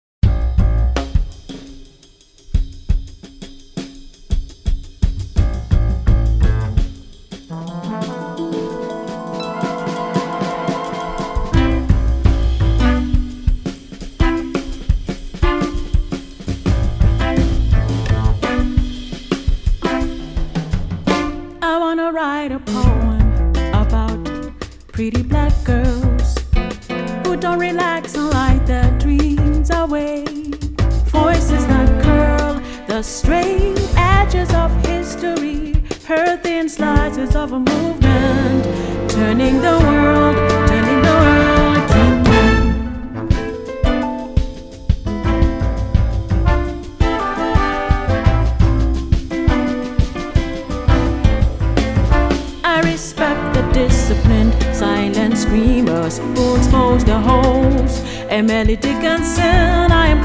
de son sax ténor